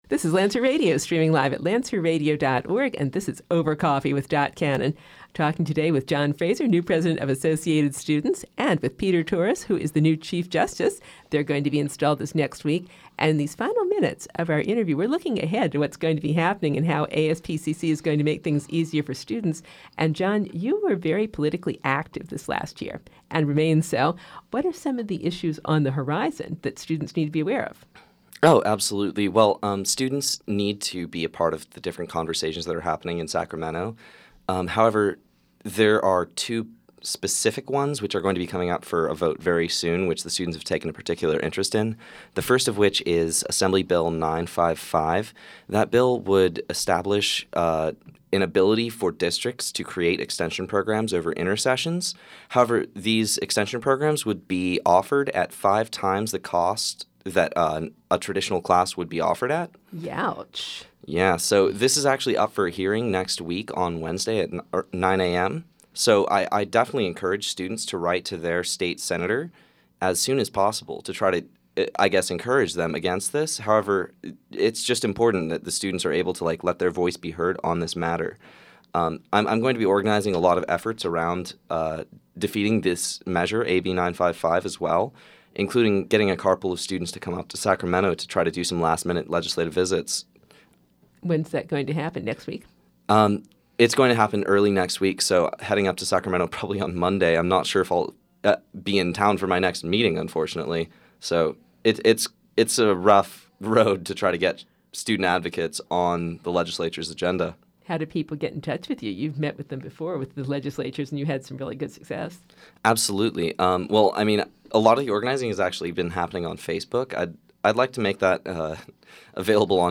Associated Students Interview, Part Three